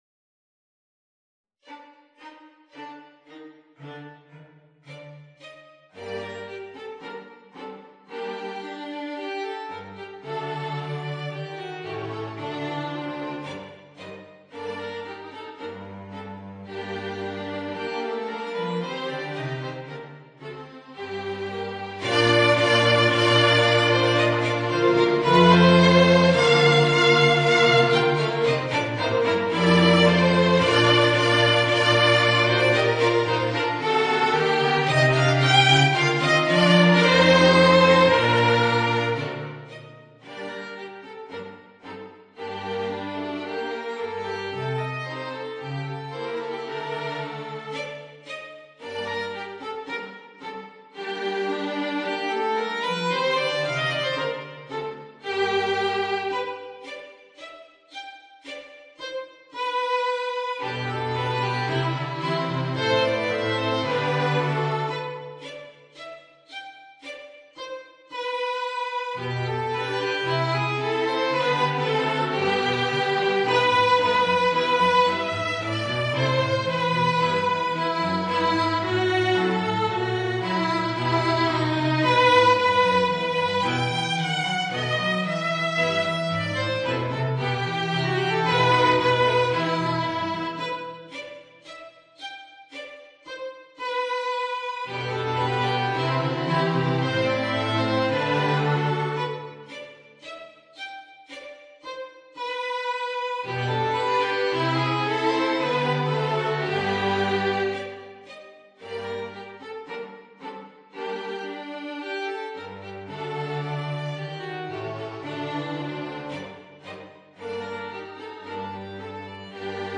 Voicing: Viola and String Quartet